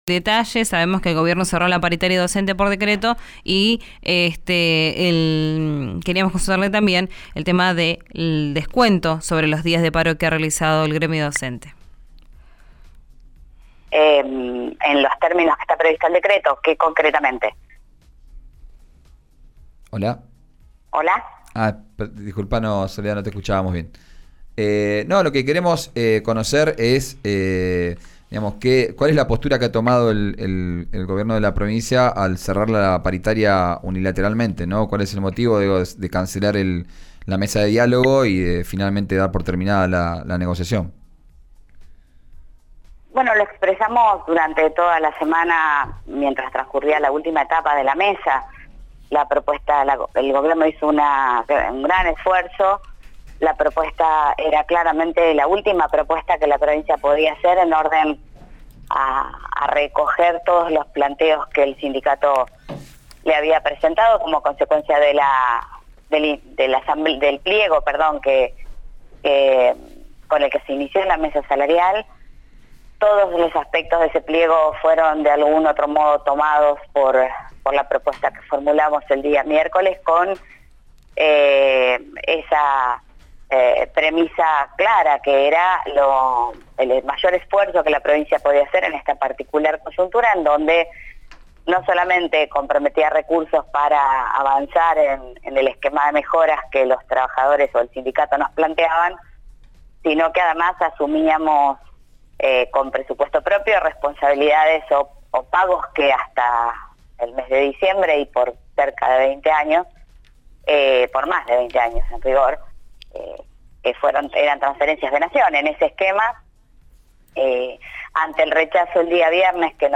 Escuchá a la ministra de Educación Soledad Martínez en RÍO NEGRO RADIO: